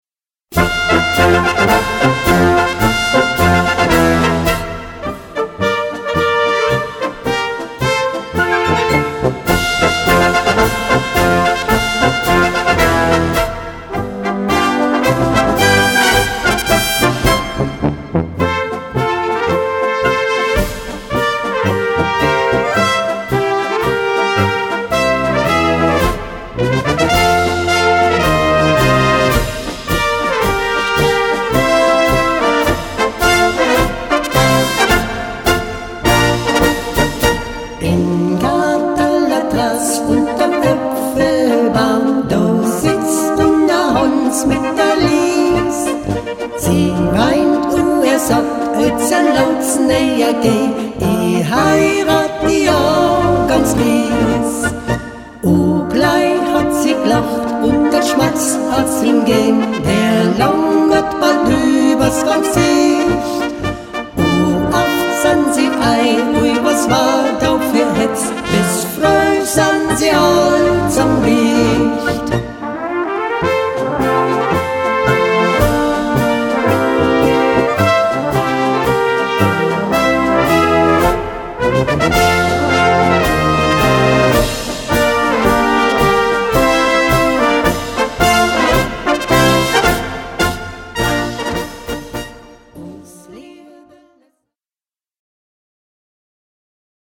Marschlied mit Gesang
Besetzung: Blasorchester